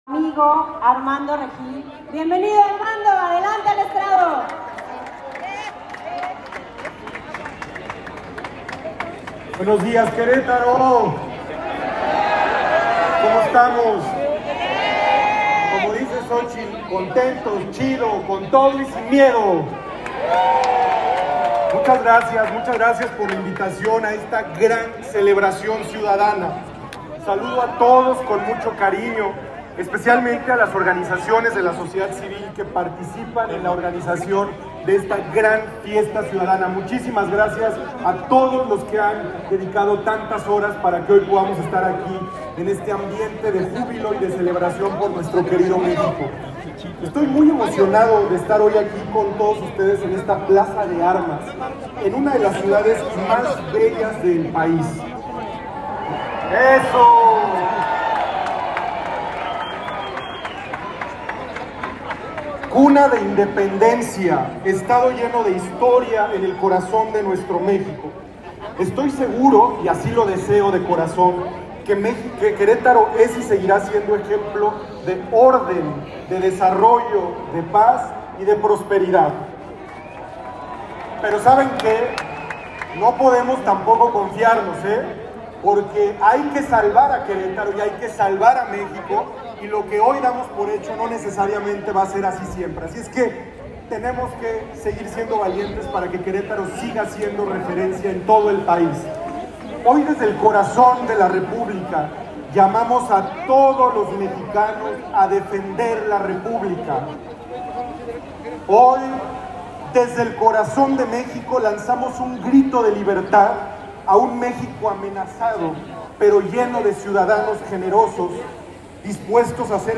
Fuera Morena, grita la multitud durante discurso
en Querétaro